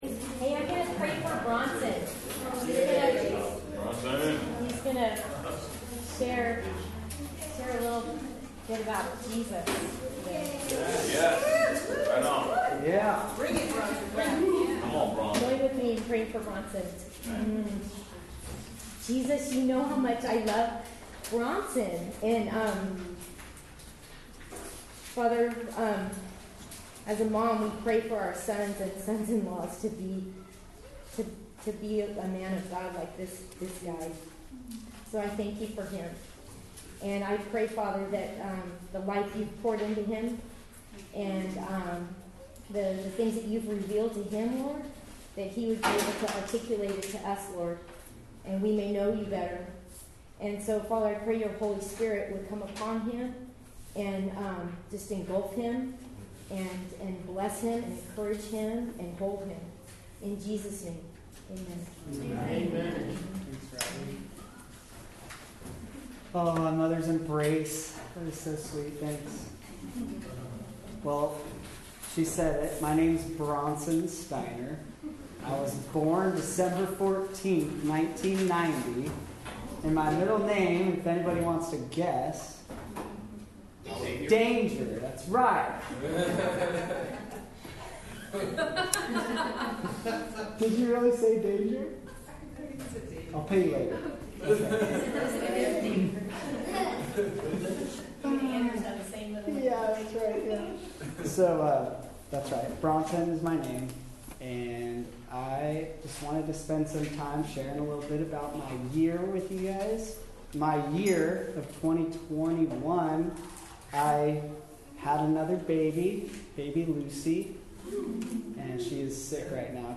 1 John 4:8-21 Service Type: Sunday Morning Related « The Great Invasion